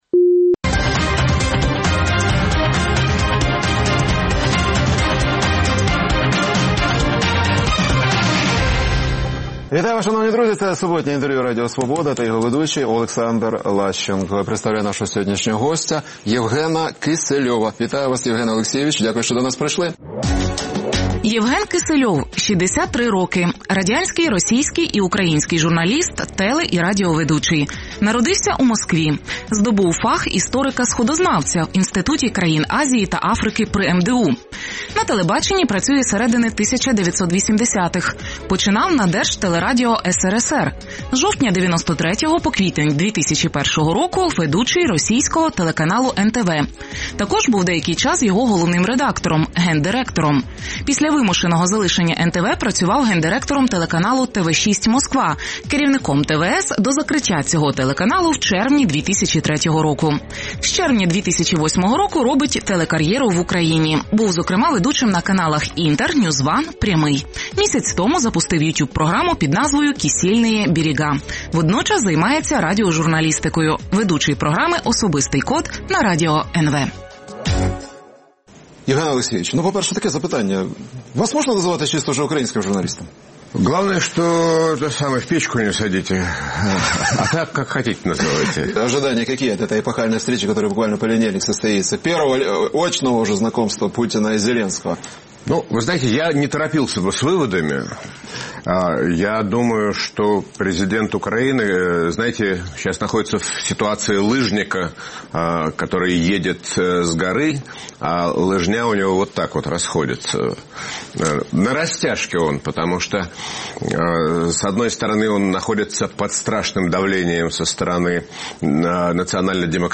Суботнє інтерв’ю | Євген Кисельов, журналіст